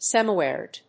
アクセント・音節sèmi・árid